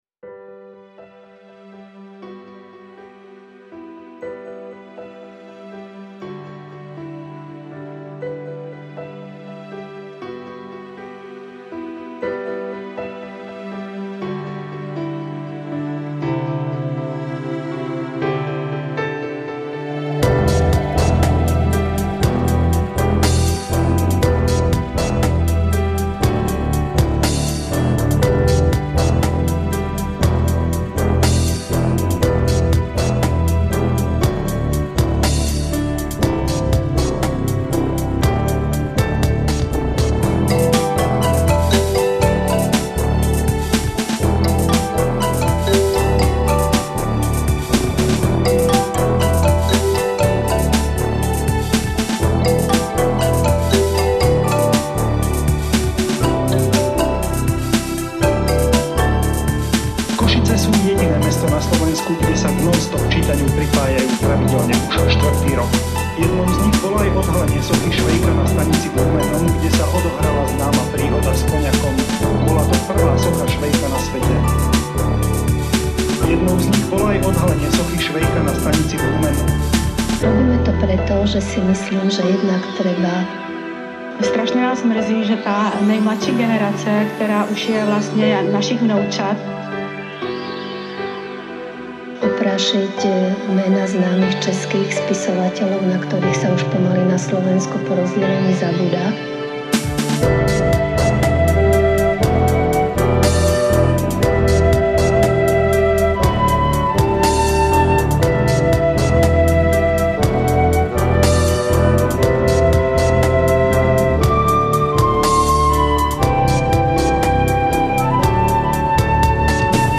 Il mastering è stato effettuato mediante sistema Creamware: la catena comprende un EQ a 32 bande, PSYQ(enhancer psicoacustico) e Optimaster (compressore multibanda)
Direi che si tratta di un brano abbastanza "new age", anche questo scritto molto tempo fa e rimaneggiato per l'occasione. In questa nuova versione ho inserito delle voci registrate dalla televisione slovacca, molto suggestive secondo il mio parere (specie la voce femminile) e uno dei flauti tradizionali del posto che si chiama Fujarka